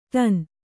♪ ṭan